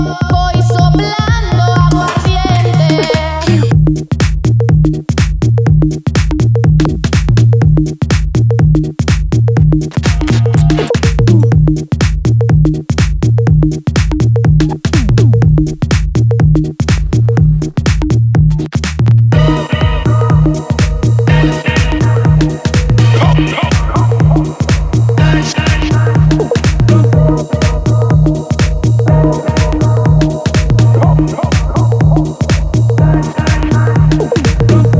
Жанр: Хаус
# House